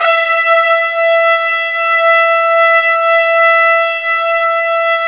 Index of /90_sSampleCDs/Roland LCDP06 Brass Sections/BRS_Tpts mp)f/BRS_Tps Swel %wh